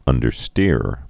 (ŭndər-stîr)